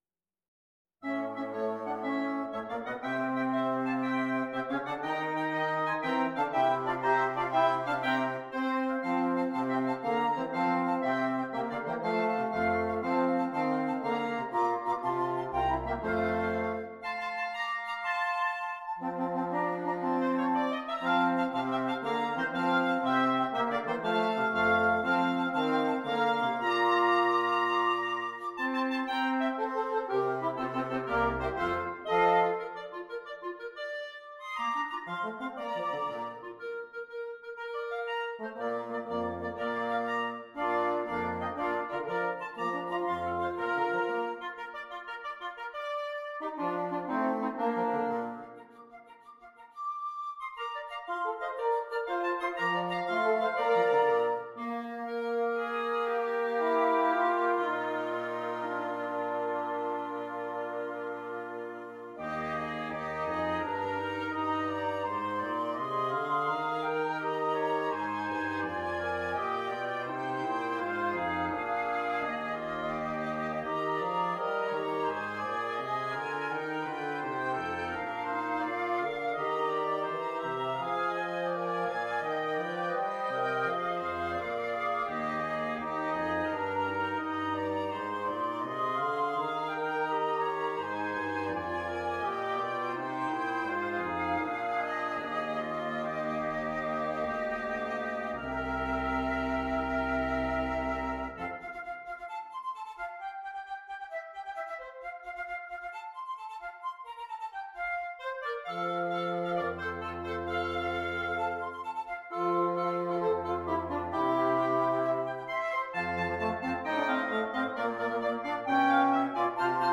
Woodwind Quintet
Traditional
Great for kiddie concerts played by a working quintet.